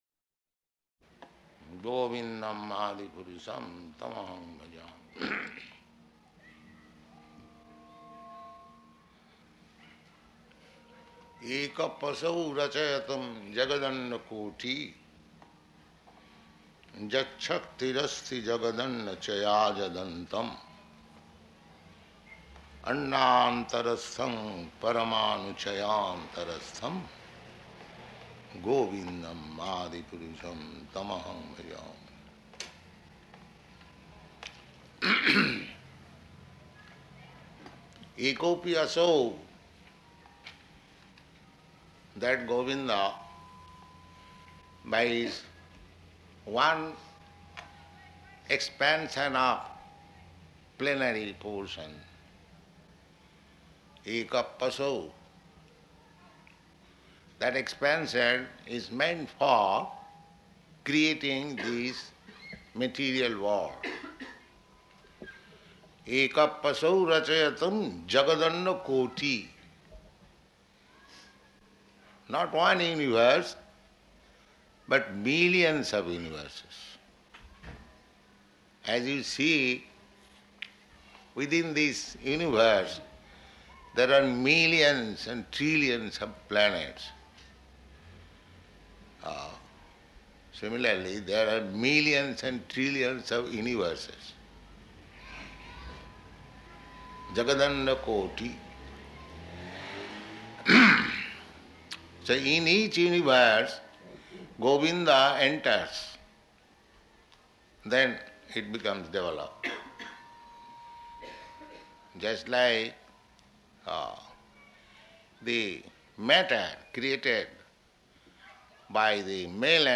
Location: Gainesville